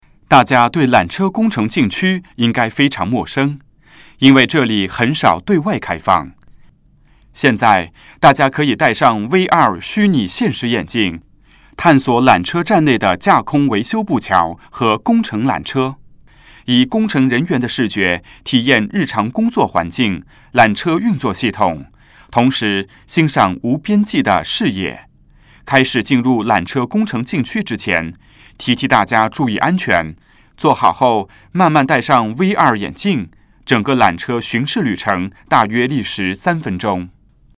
缆车探知馆语音导赏 (普通话)